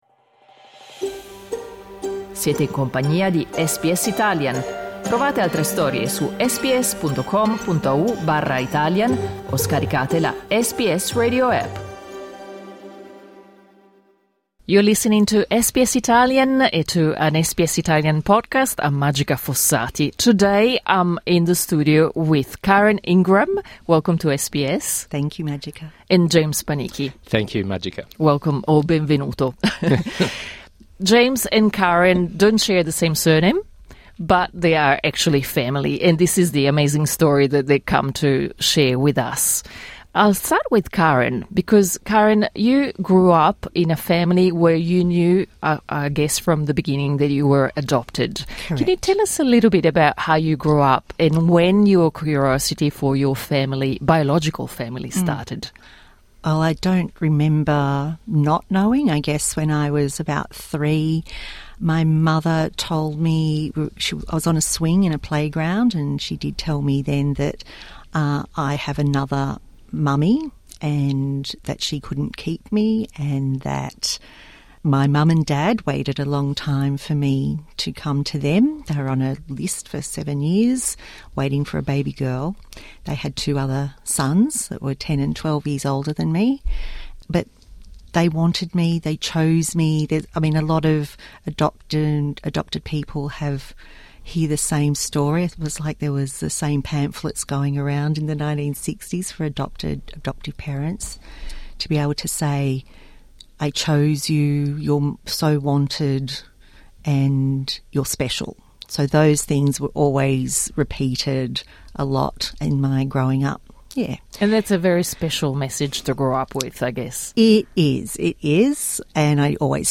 l'intervista integrale in inglese (full interview)